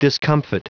Prononciation du mot discomfit en anglais (fichier audio)
Prononciation du mot : discomfit